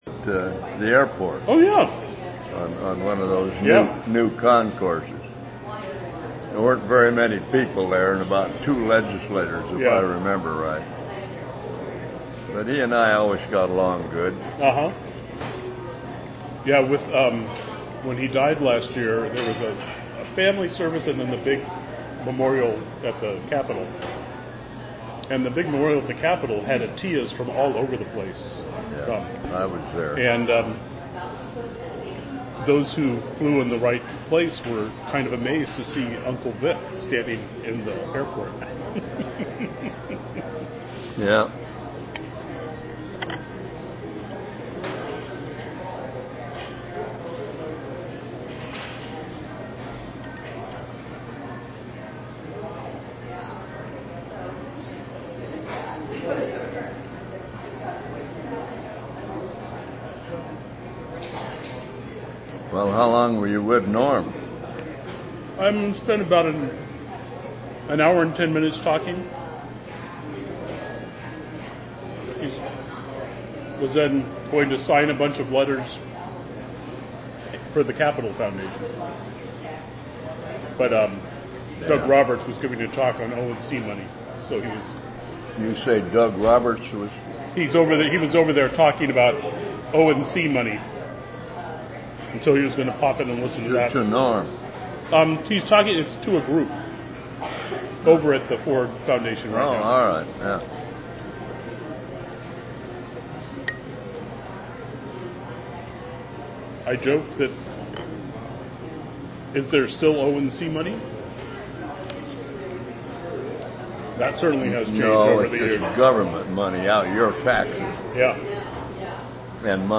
8b6d54dccff2f1f2e3acad789d49f6a59113bc9c.mp3 Title Bill Markham interview on Atiyeh Description An interview of William Edwin Markham (1922-2021) regarding Oregon's Governor Victor Atiyeh, recorded on Apr. 28, 2015. Markham served as a Republican in Oregon's House of Representatives from 1969 to 1999.